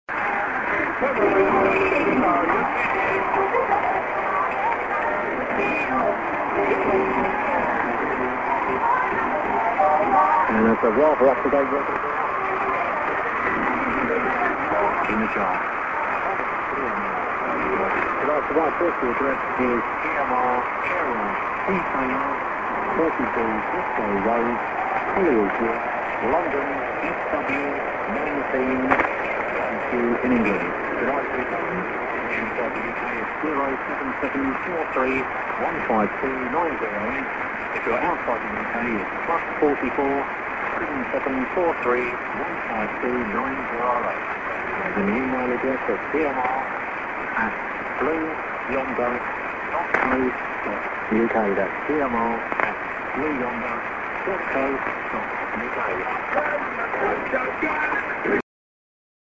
music->ID@00'20"->music